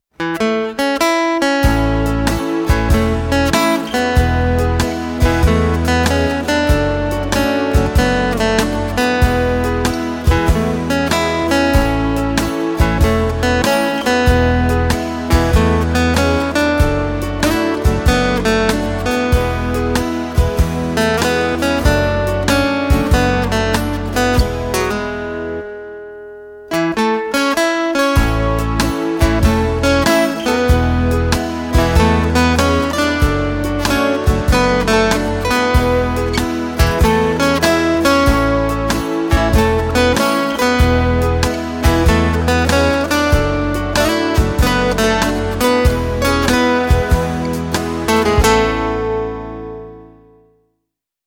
背景音乐为舒缓惬意午后放松背景配乐
该BGM音质清晰、流畅，源文件无声音水印干扰